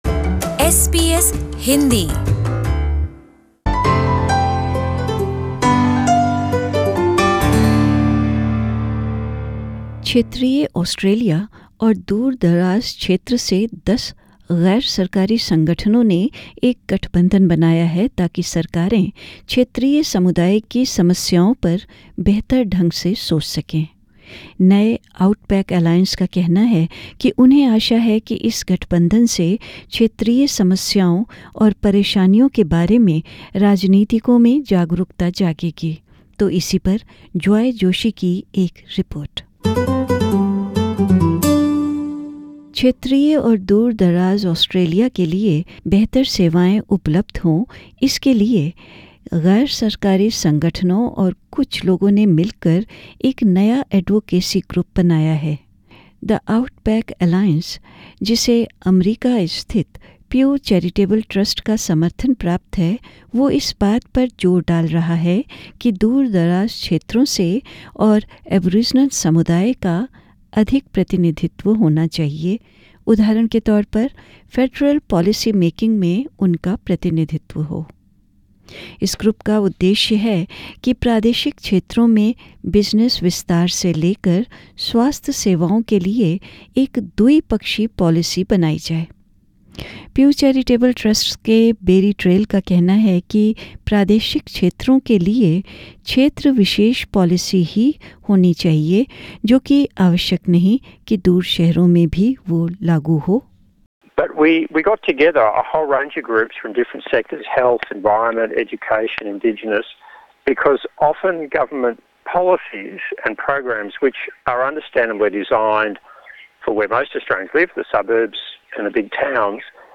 यह रिपोर्ट...